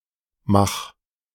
Ernst Waldfried Josef Wenzel Mach (/mɑːk/ MAHK;[2] Austrian German: [ˈɛrnst ˈmax]